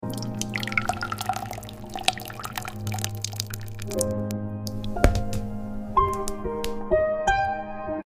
ASMR Smooth melted chocolate sound effects free download